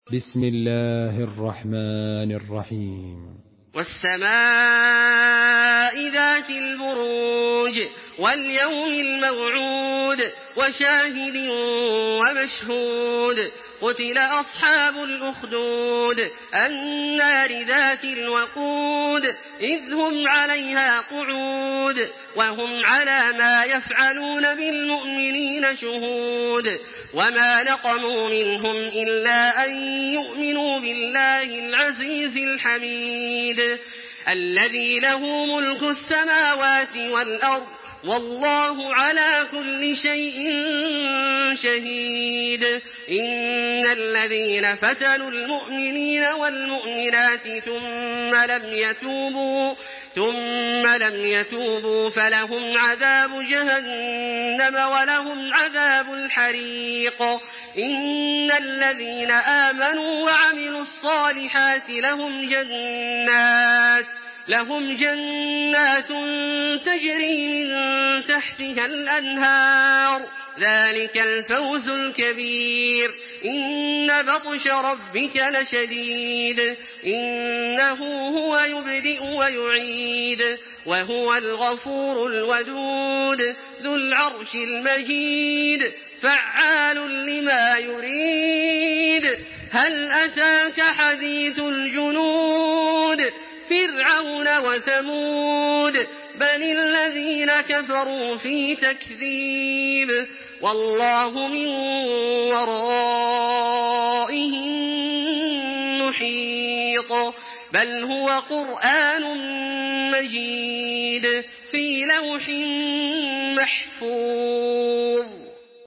Surah Al-Buruj MP3 in the Voice of Makkah Taraweeh 1426 in Hafs Narration
Listen and download the full recitation in MP3 format via direct and fast links in multiple qualities to your mobile phone.
Murattal Hafs An Asim